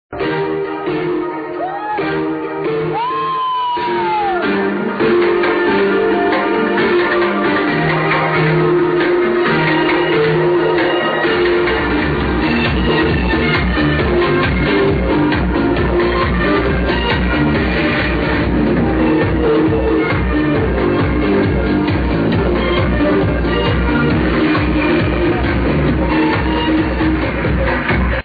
Does any1 know the name of this house track from 2002??